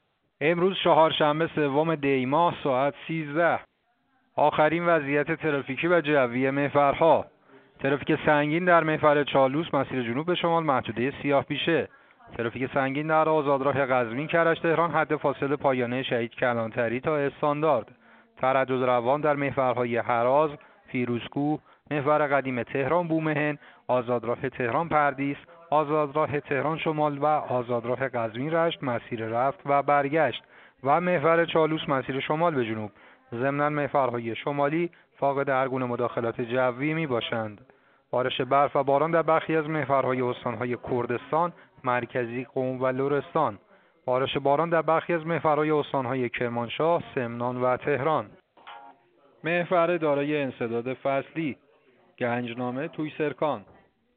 گزارش رادیو اینترنتی از آخرین وضعیت ترافیکی جاده‌ها ساعت ۱۳ سوم دی؛